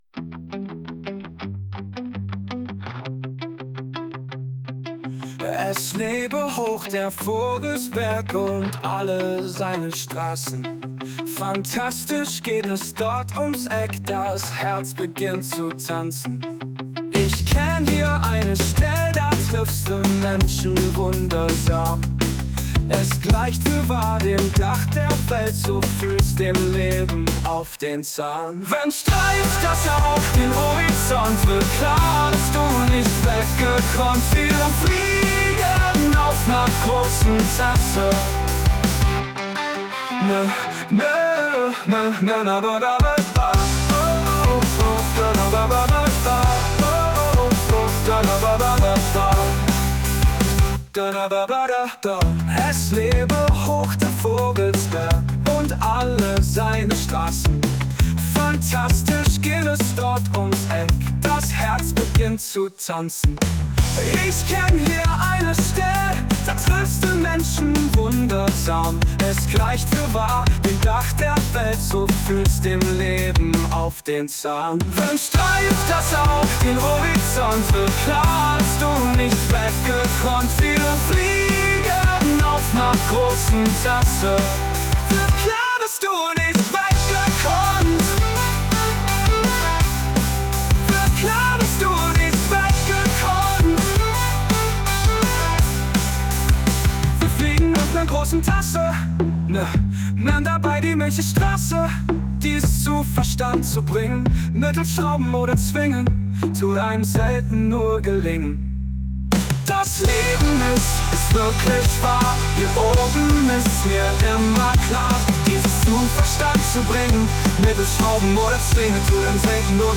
Und so klingt es als Song: